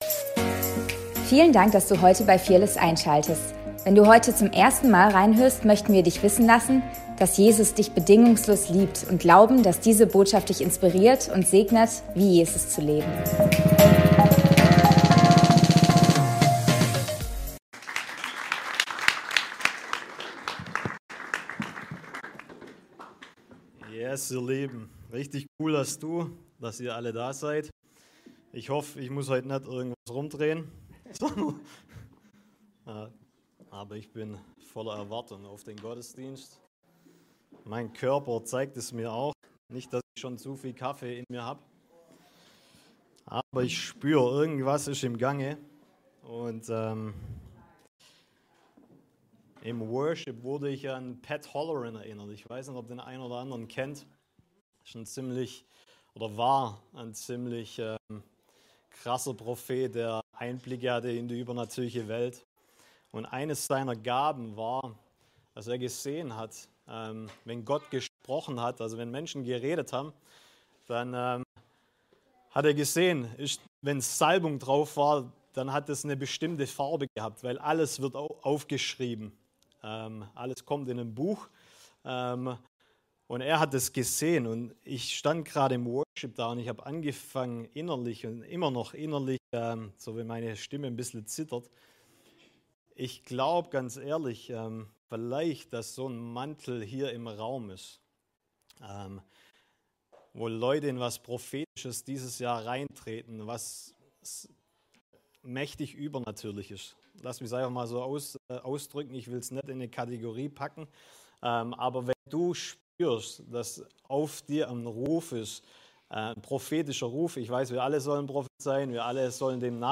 Predigt vom 12.01.2025